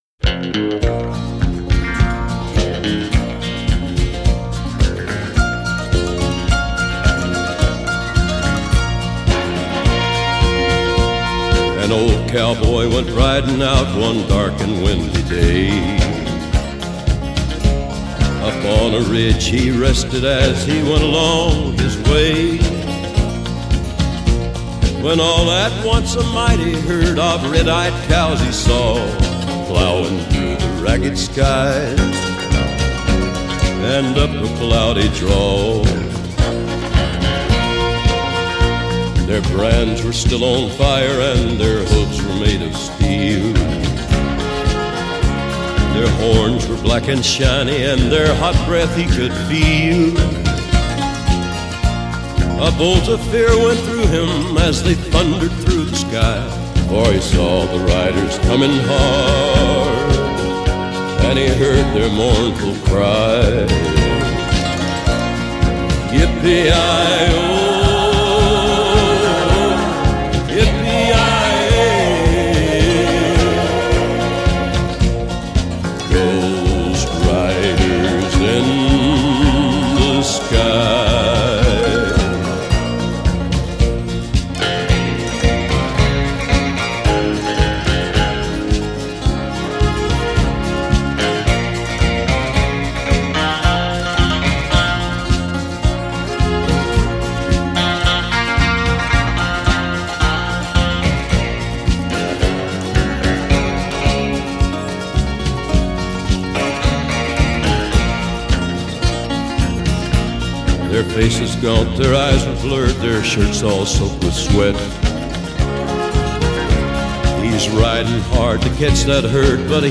کانتری country